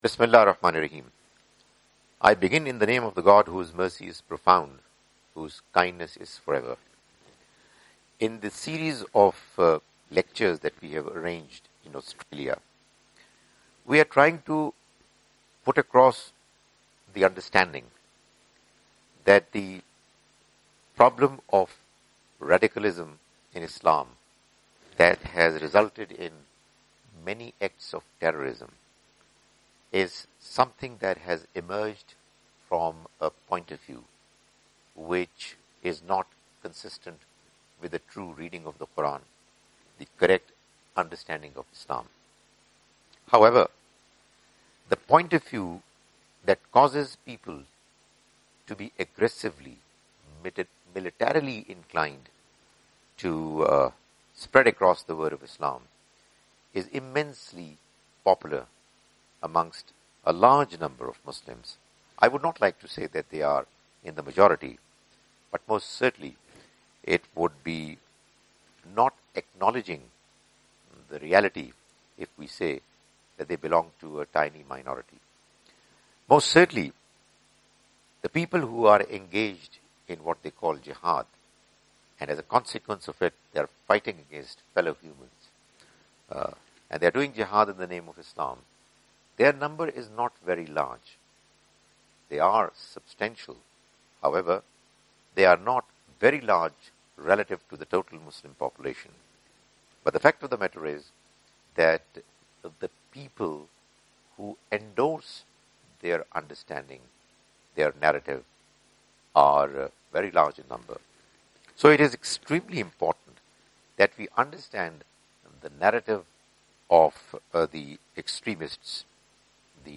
A series of 9 Lecture
Australian Tour 2017: Sydney - Canberra - Melbourne